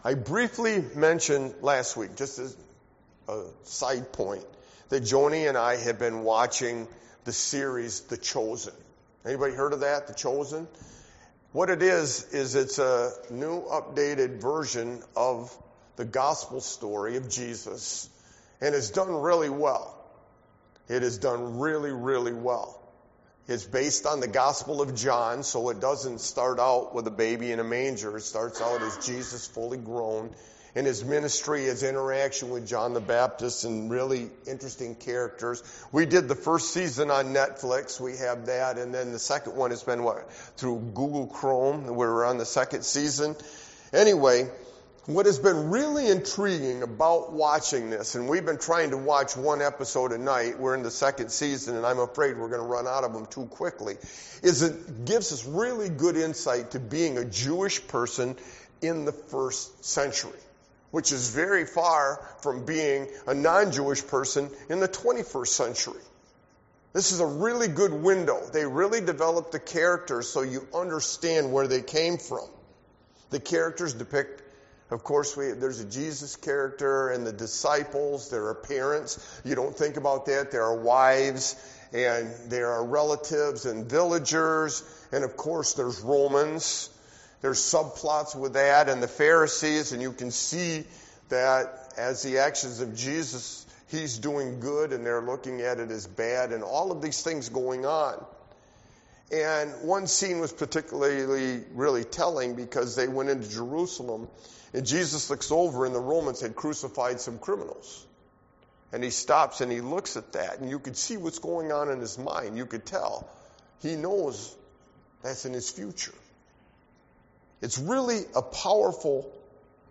Sermon-Why-a-Sovereign-God-is-crucial-V-21322.mp3